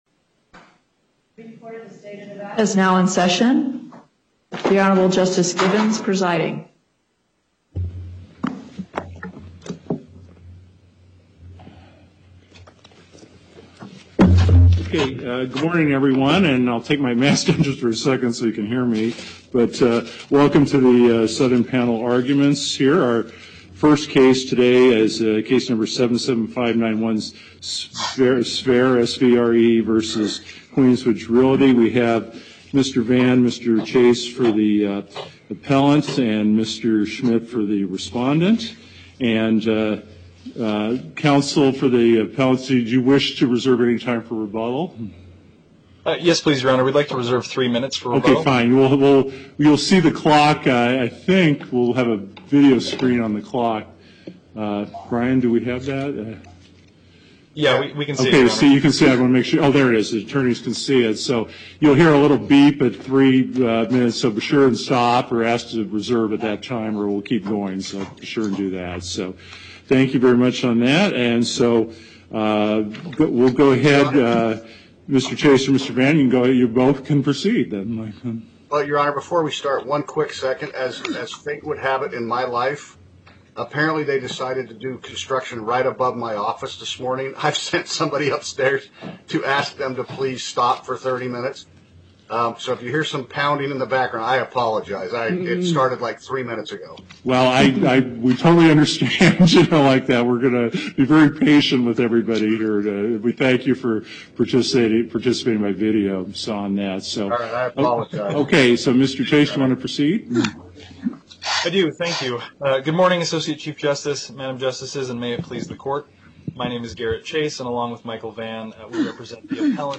Las Vegas Before the Southern Panel, Justice Gibbons presiding Appearances